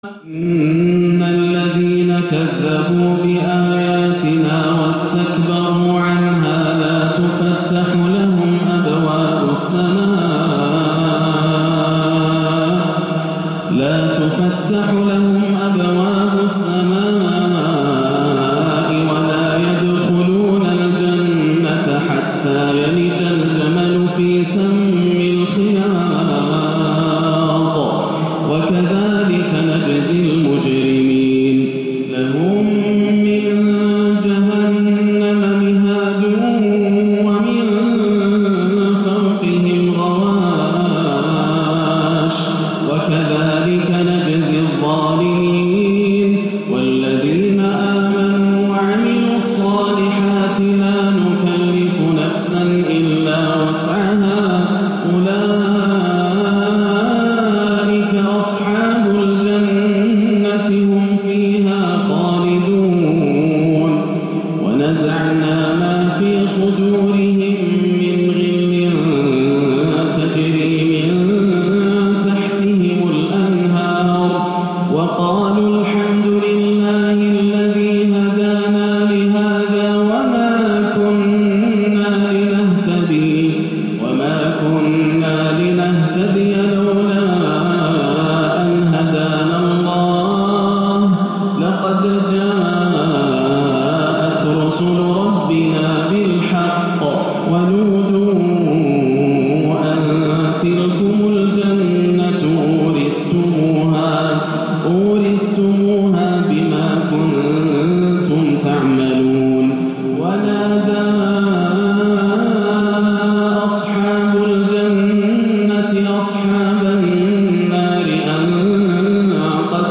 (ونَادَى أَصْحَابُ الجَنة) حوار أهل النعيم و أهل الجحيم - تلاوة عراقية باكية - السبت 11-5 > عام 1437 > الفروض - تلاوات ياسر الدوسري